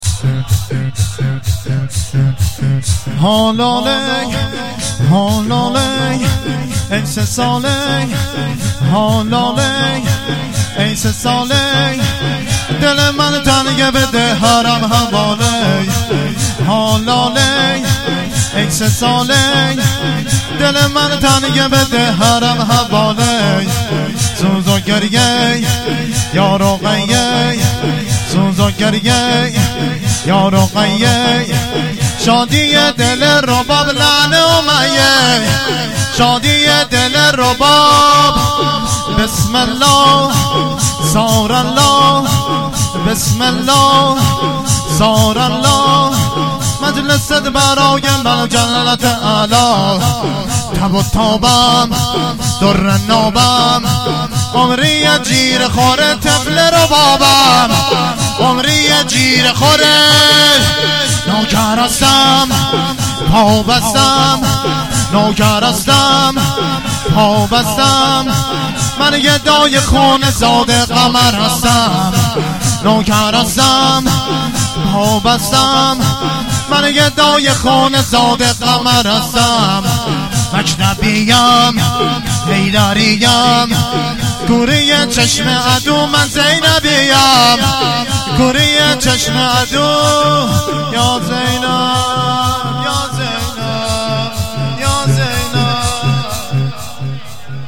مراسم هفتگی ۳۰ آبان هییت عاشقان ثارالله اشتراک برای ارسال نظر وارد شوید و یا ثبت نام کنید .